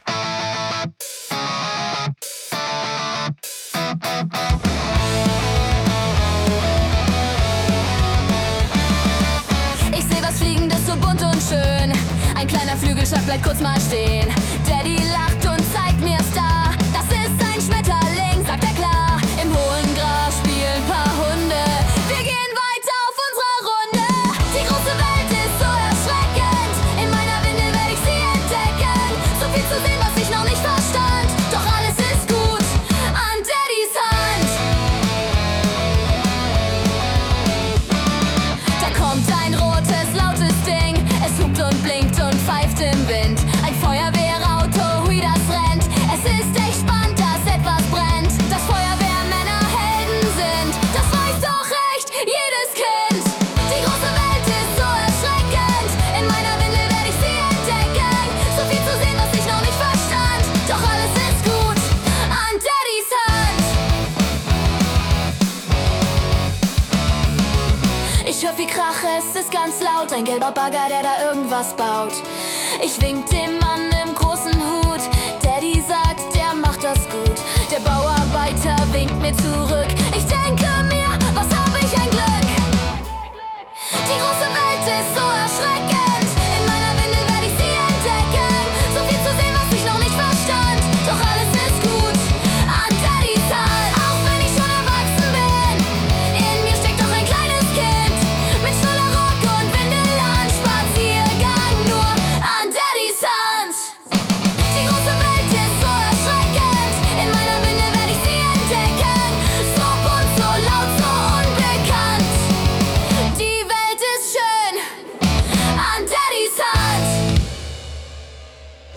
Genre: Punkrock
Mit leichtem, fast lullabyhaftem Ton